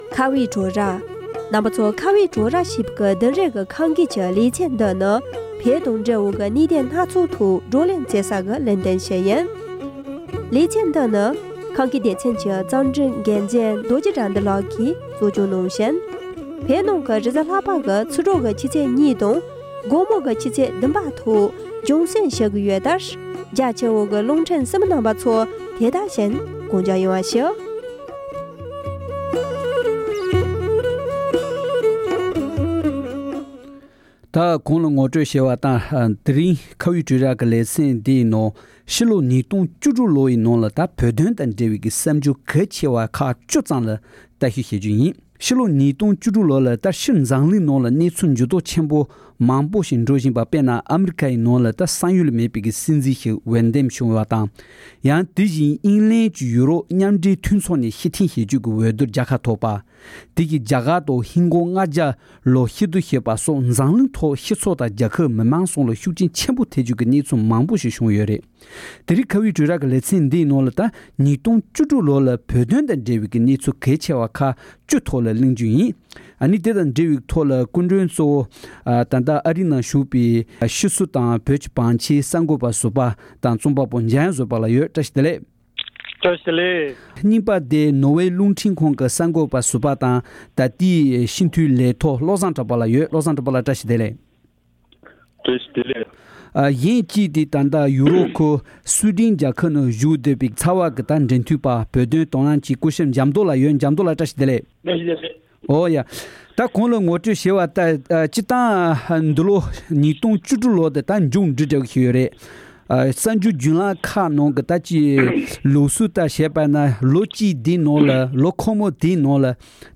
སྐུ་མགྲོན་གསུམ་ཡོད།